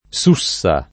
Sussa [ S2SS a ] → Susa